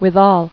[with·al]